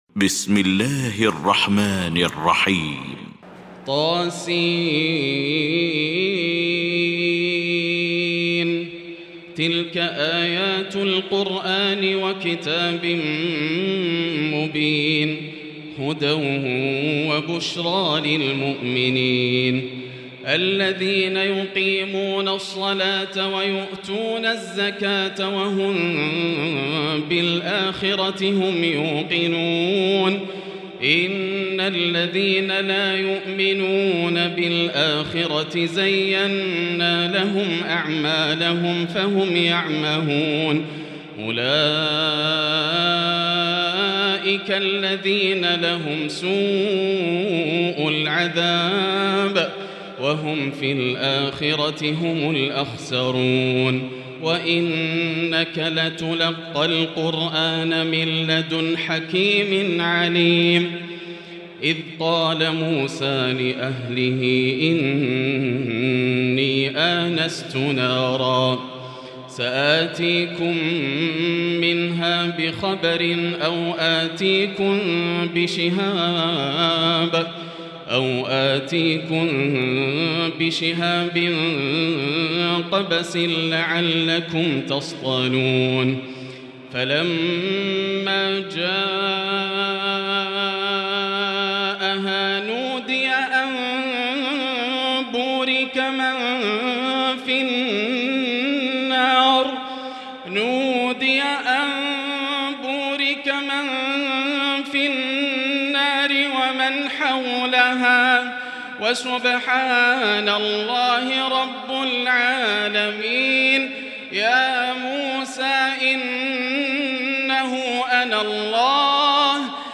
المكان: المسجد الحرام الشيخ: فضيلة الشيخ ماهر المعيقلي فضيلة الشيخ ماهر المعيقلي فضيلة الشيخ ياسر الدوسري النمل